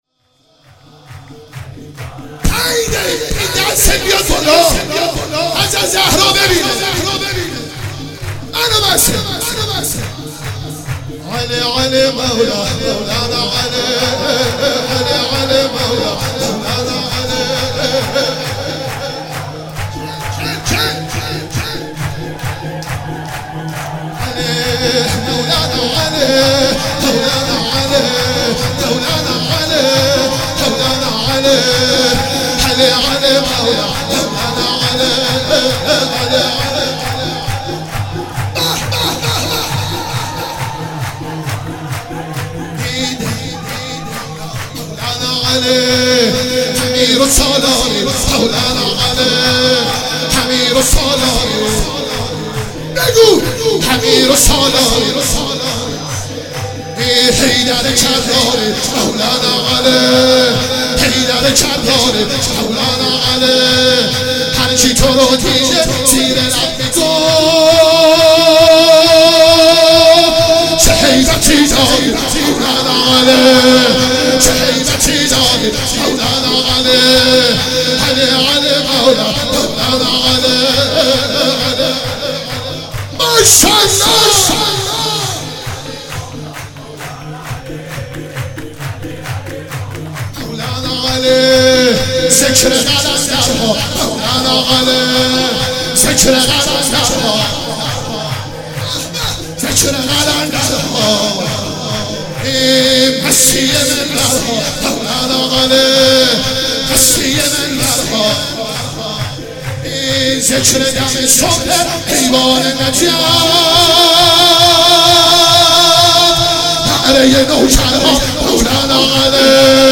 مراسم شب میلاد امام رضا(ع) 96
شور
سرود